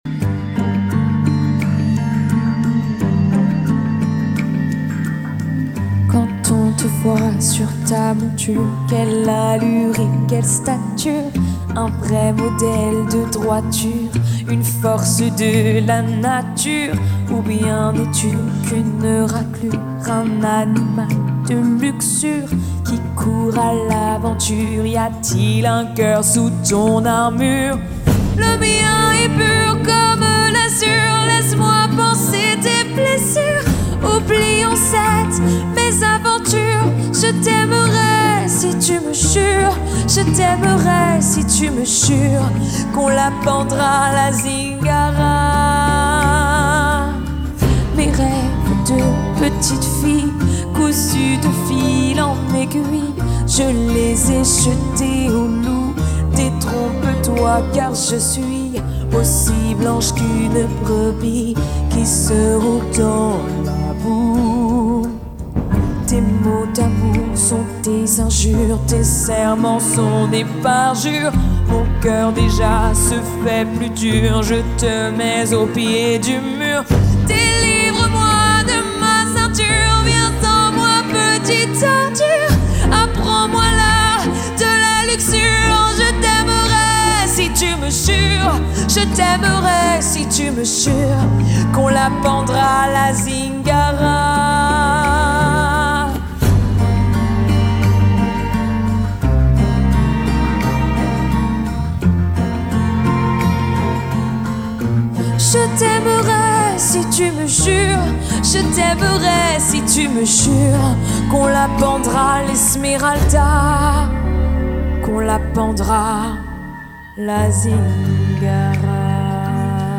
20 - 30 ans - Mezzo-soprano